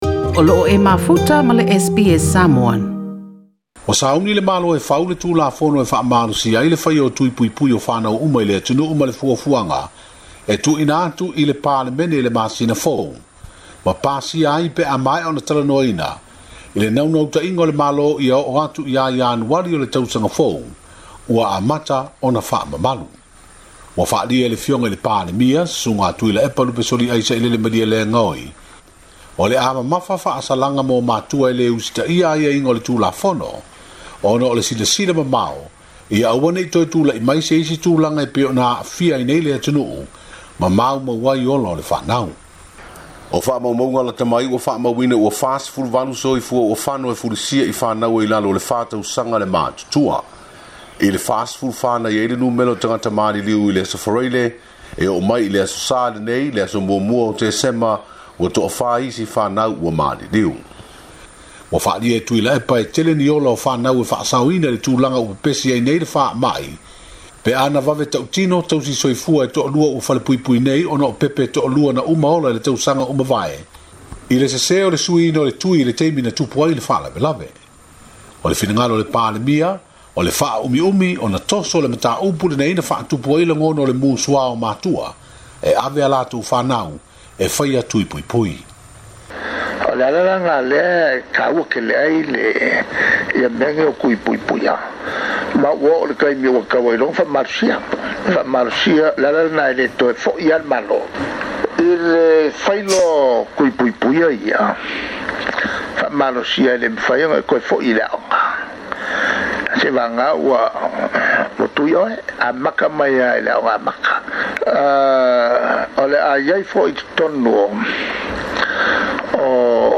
SBS Samoan